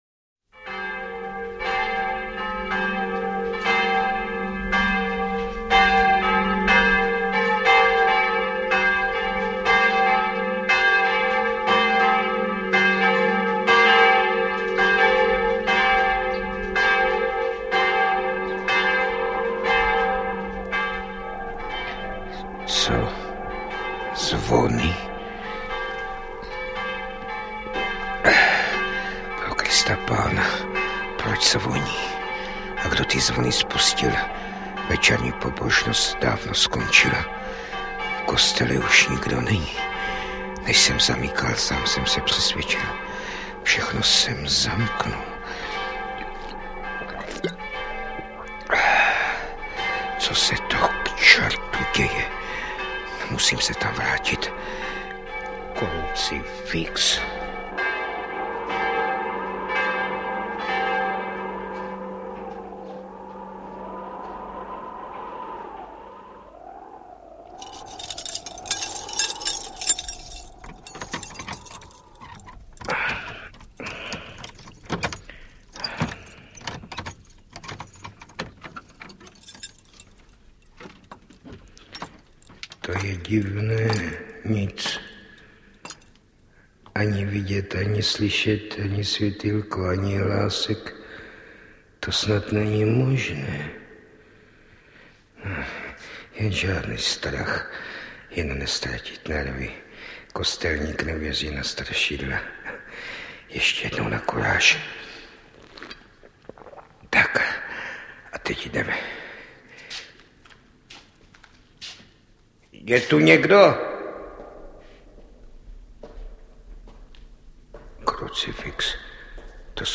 Napínavý a strhující román byl natočen jako rozhlasová hra v roce 1967 v Brně, pro velký zájem posluchačů ho Český rozhlas Vltava dodnes pravidelně reprízuje.